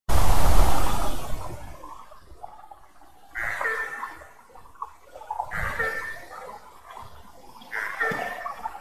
Krauklis, Corvus corax
StatussDzirdēta balss, saucieni
PiezīmesJūras ērgļi (Haliaeetus albicilla) Durbē - LDF tiešraide